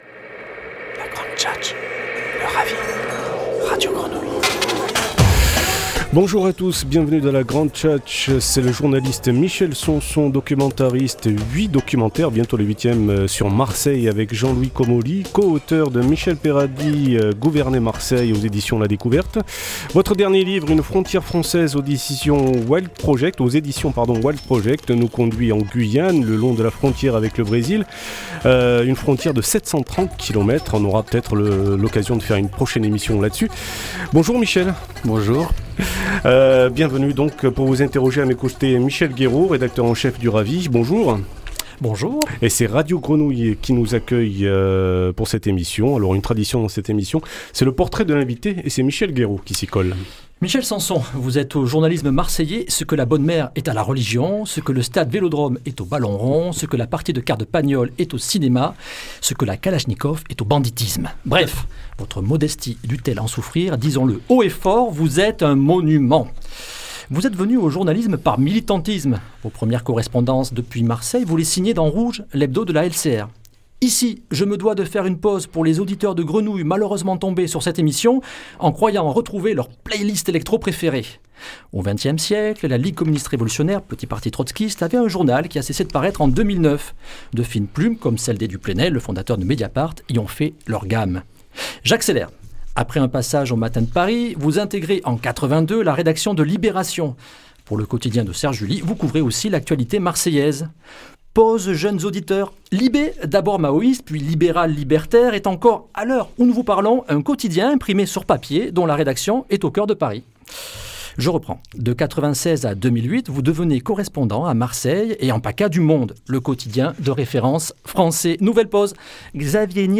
Entretien radio en partenariat avec Radio Grenouille